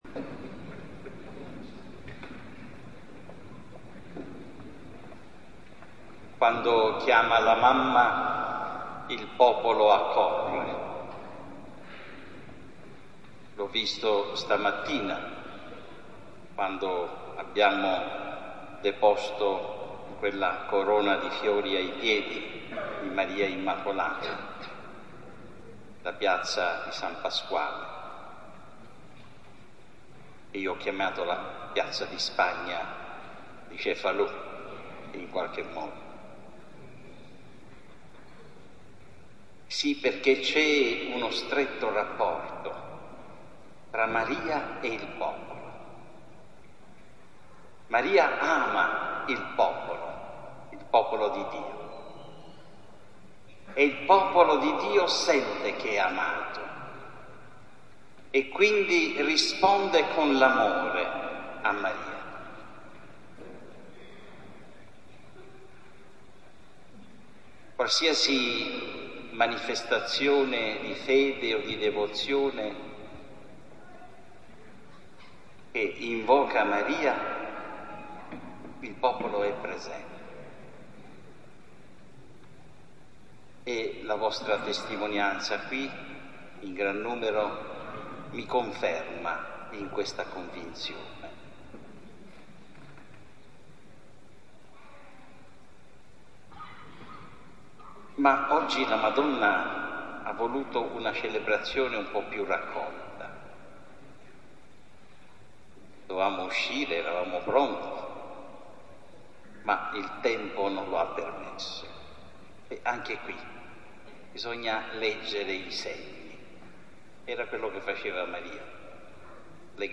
VIDEO-FOTO del Solenne Pontificale con l'AUDIO dell'omelia del Vescovo
AUDIO: L'Omelia del Vescovo VIDEO-FOTO del Solenne Pontificale con l'AUDIO dell'omelia del Vescovo